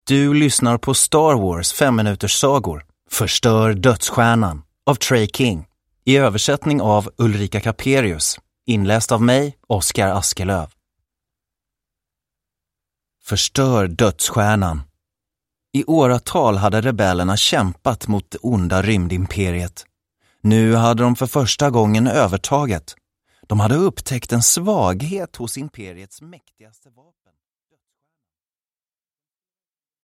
Förstör dödsstjärnan! Femte delen ur Star Wars 5-minuterssagor – Ljudbok – Laddas ner